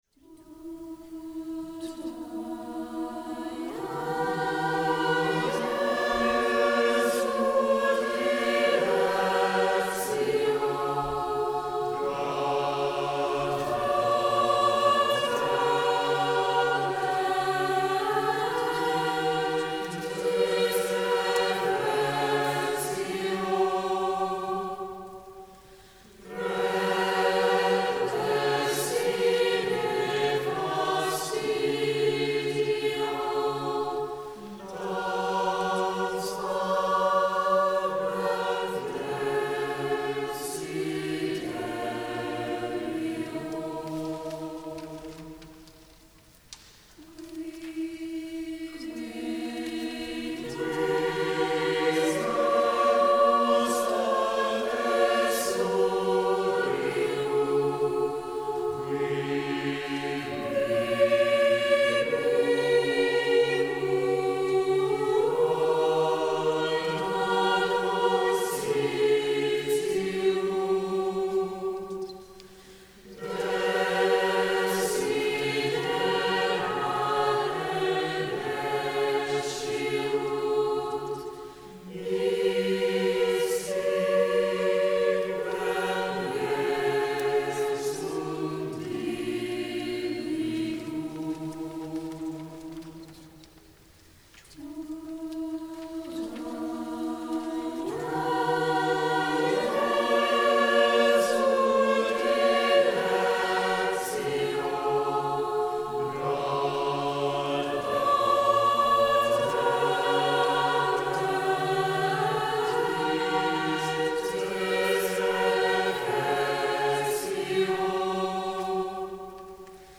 Tua Jesu Dilectio (SAB)
This lesser-known gem is filled with warm, tender imitation, likely written for a smaller Roman chapel or devotional gathering.
Live recording • Tua Jesu Dilectio